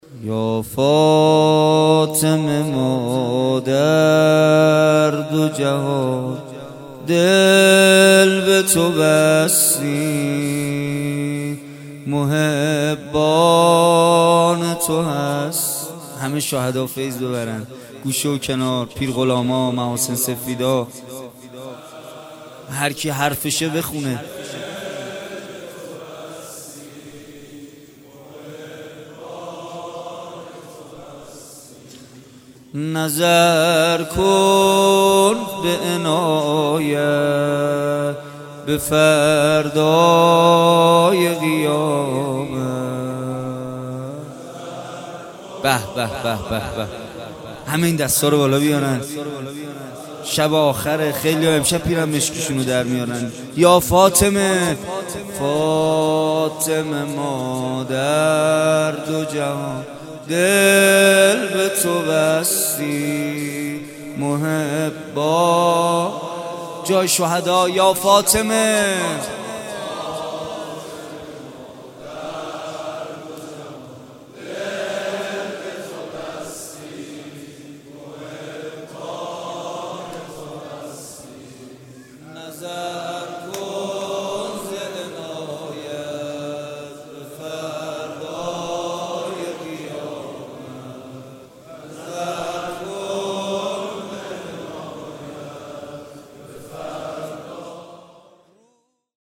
یا فاطمه ما در دو جهان دل به تو بستیم | نوحه | کربلایی محمدحسین پویانفر